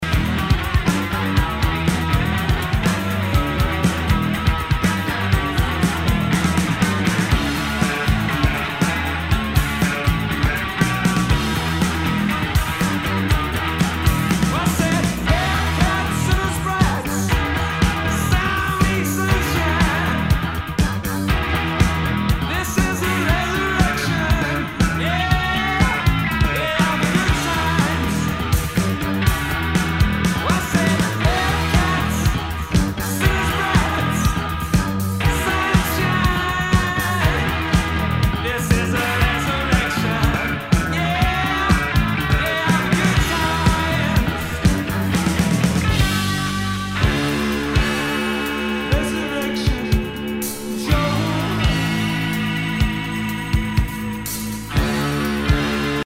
ROCK/POPS/INDIE
80’s ロック！
[VG ] 平均的中古盤。スレ、キズ少々あり（ストレスに感じない程度のノイズが入ることも有り）